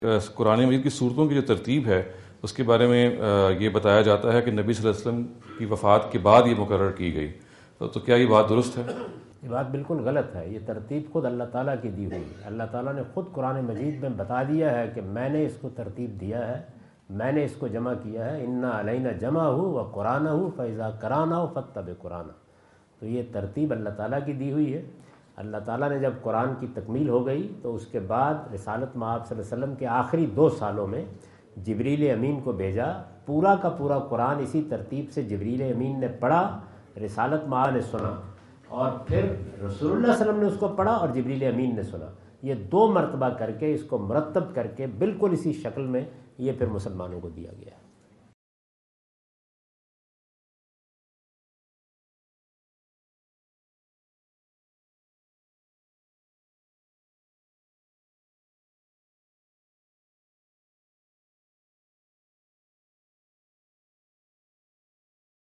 Javed Ahmad Ghamidi answer the question about "Arrangement and Sequence of Quranic Chapters" asked at Corona (Los Angeles) on October 22,2017.
جاوید احمد غامدی اپنے دورہ امریکہ 2017 کے دوران کورونا (لاس اینجلس) میں "قرآن کی ترتیب و تدوین" سے متعلق ایک سوال کا جواب دے رہے ہیں۔